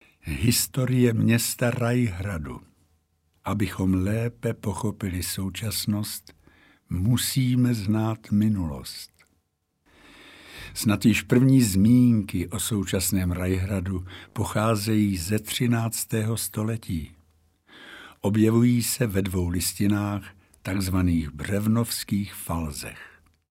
Mluvené slovo / Reklama
Radovan Lukavský  “Herec pražského Národního divadla“